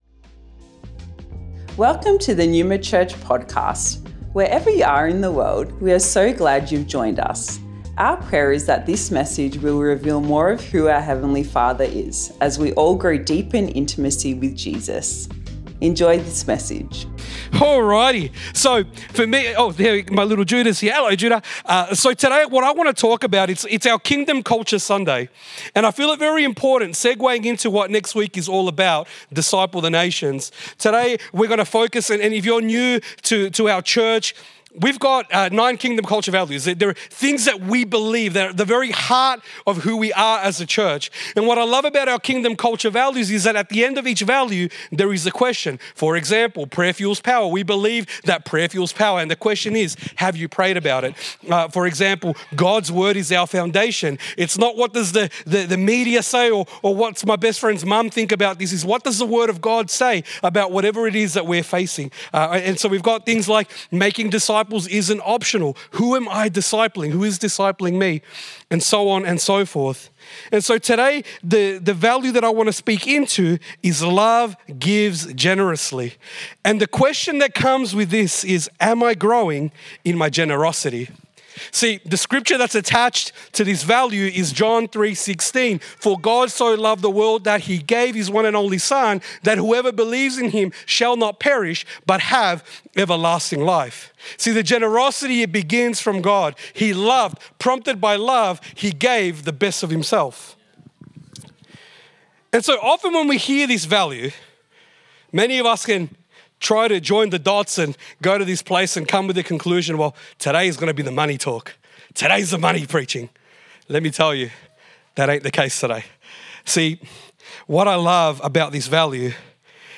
Originally recorded at Neuma Melbourne West Sept 8th 2024